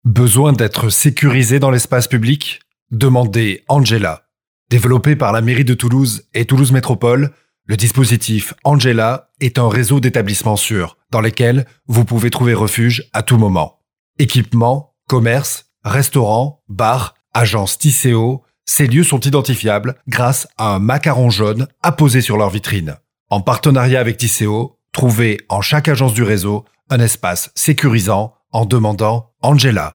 Doc 31 - Tisséo Toulouse Spot-audio-Angela.mp3